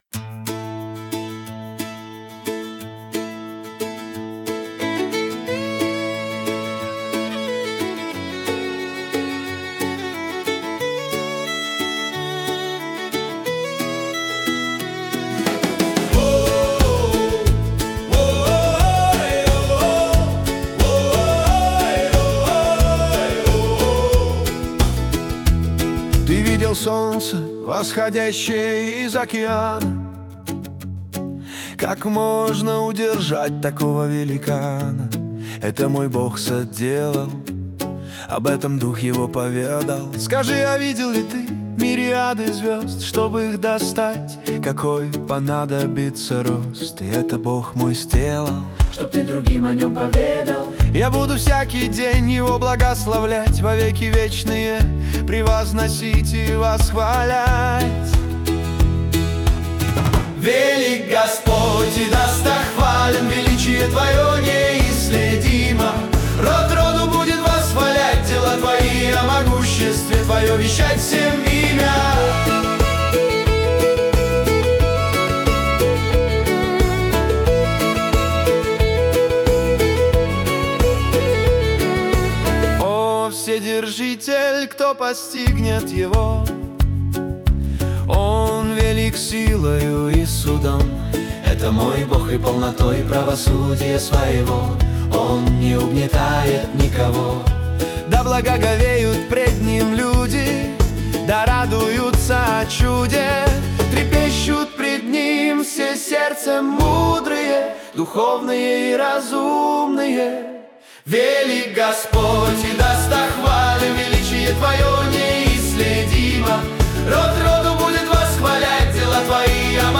песня ai
354 просмотра 1189 прослушиваний 109 скачиваний BPM: 90